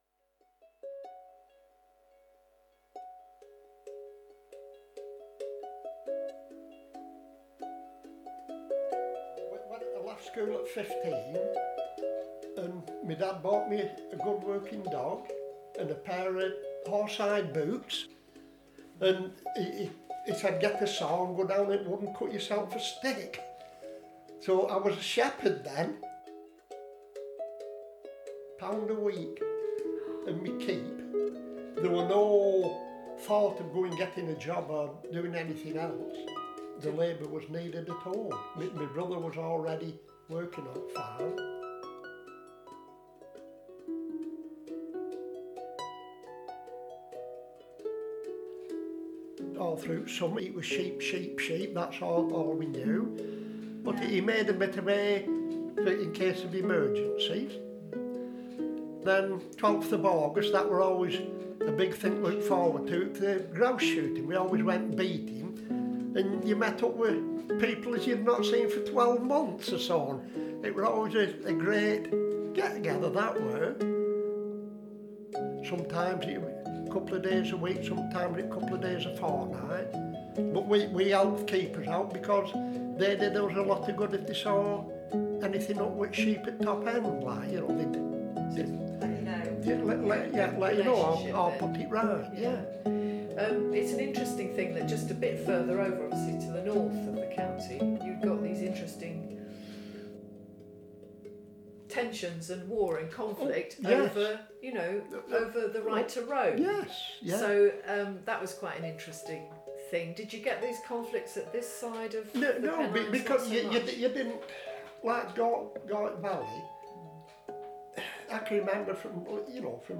Voices from the Peak: a journey through the Peak District in word and sound - enjoy the extracts from the first three audios featuring the atmospheres, wildlife and stories of this strange and beautiful land of contrasts. Headphones recommended!